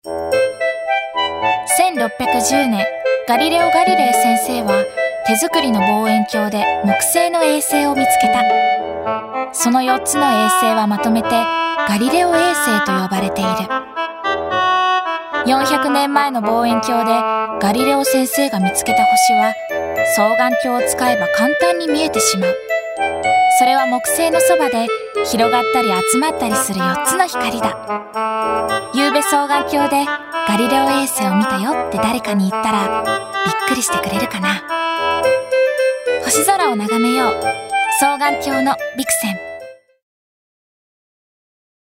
ラジオCM